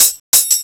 TAMBTRILP1-L.wav